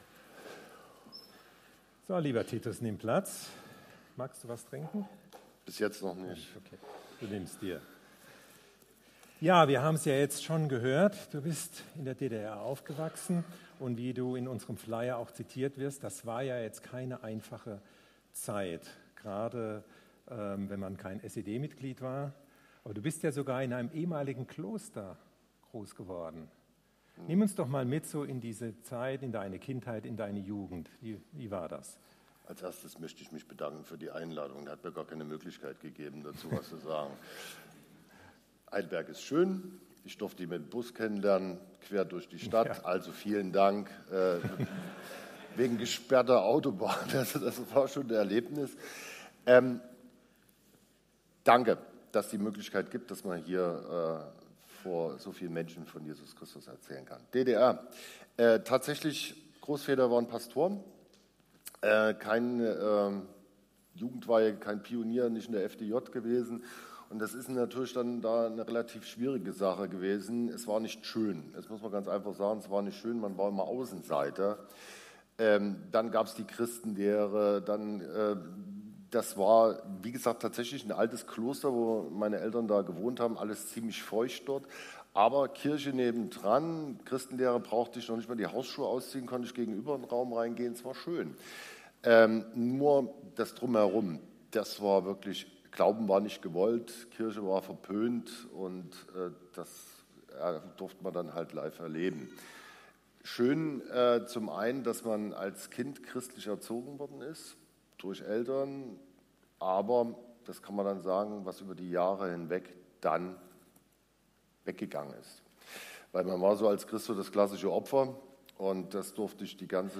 Zweite Predigt der Serie
LIFETALK LifeTalk ist das etwas andere Gottesdienst-Format der FeG Heidelberg.